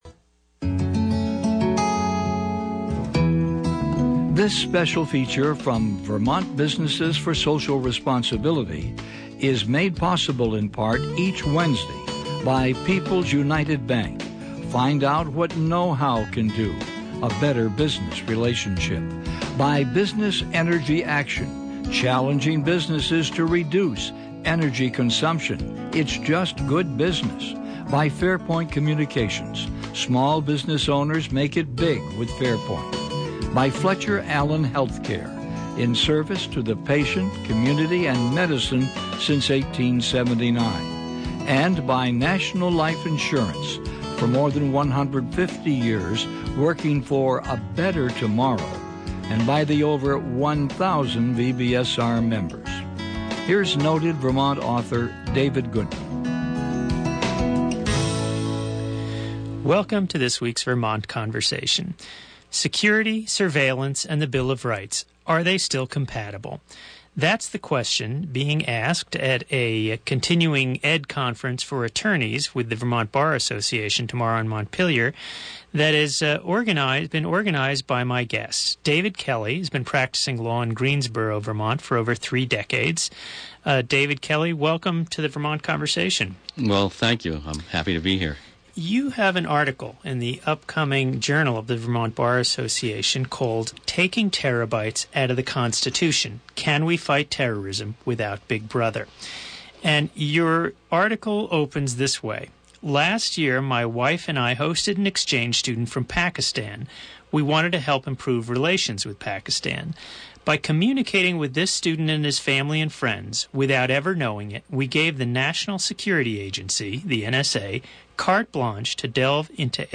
Vermont Senate Majority Leader Sen. Becca Balint (D-Windham) talks about what she considers to be one of the proudest moments of her legislative career.
Includes longer version of interview than aired on WDEV.